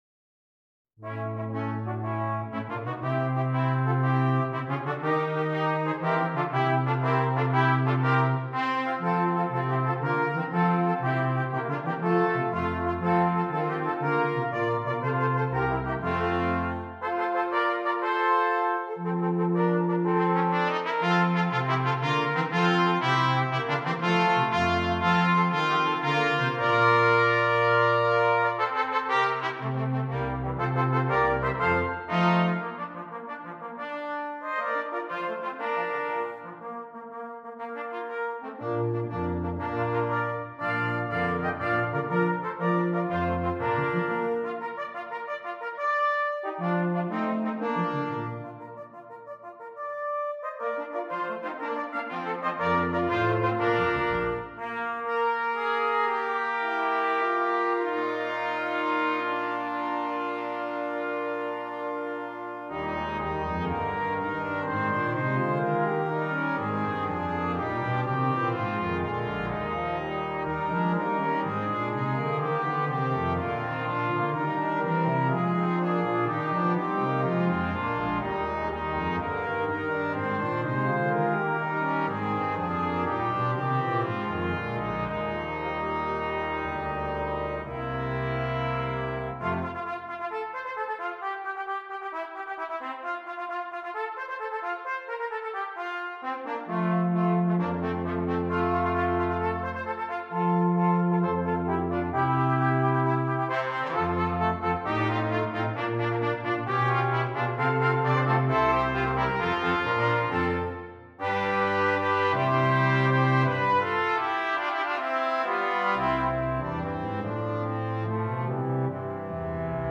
Brass Quintet
Traditional
Great for kiddie concerts played by a working quintet.